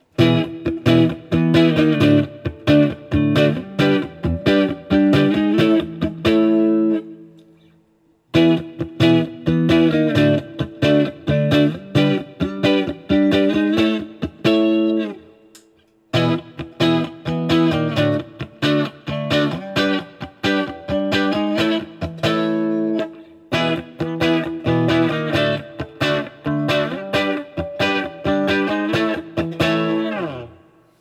All recordings in this section were recorded with an Olympus LS-10.
For each recording, I cycle through all of the possible pickup combinations, those being (in order): neck pickup, both pickups (in phase), both pickups (out of phase), bridge pickup.